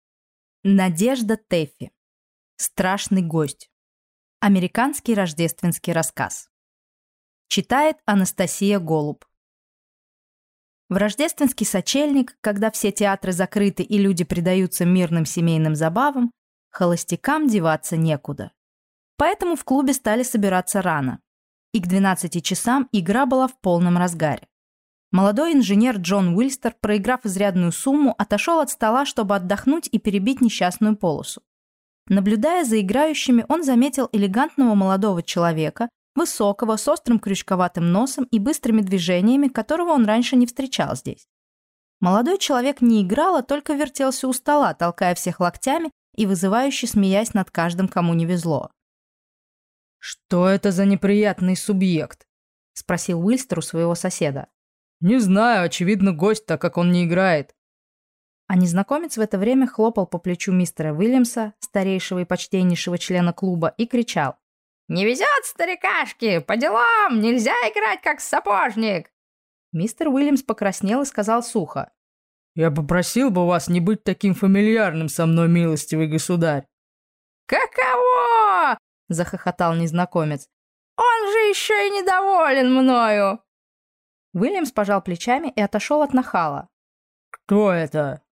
Аудиокнига Страшный гость | Библиотека аудиокниг